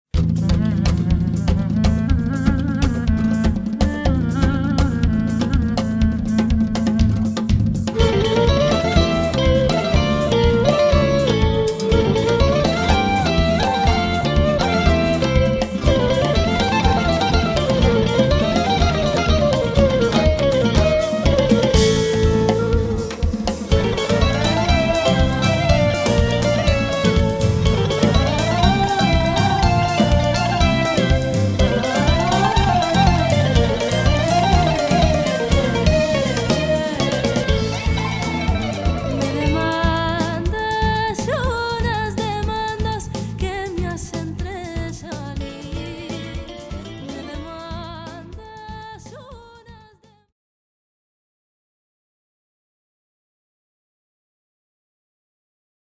Genre: Jazz.
Bouzouki with Fender Twin amplifier